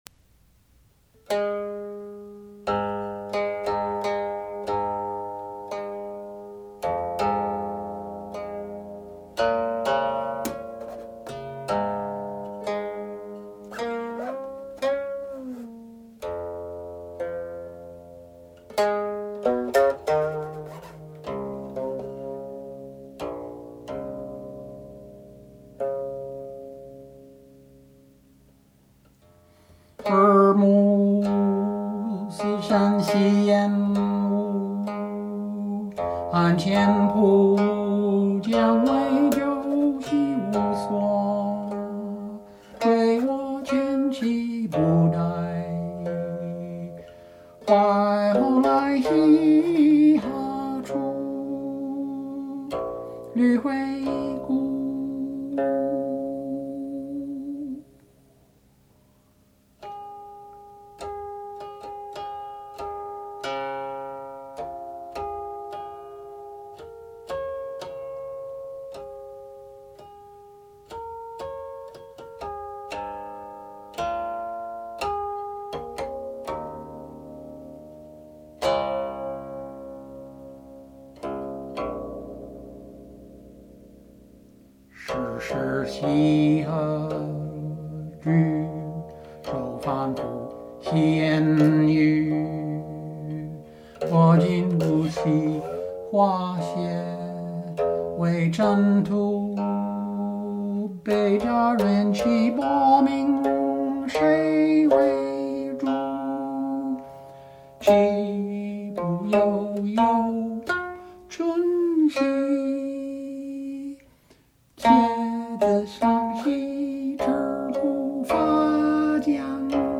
Music and Lyrics: Four sections (untitled)
(00.00; lyrics sung on repeat: 00.2915)